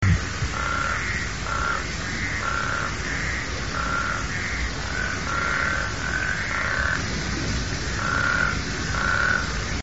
chant:
reproduction: durant la saison des pluies, les mâles se regroupent et chantent puissamment dans les points d'eau proches, temporaires ou permanents, mares, lacs ou rivières à courant calme.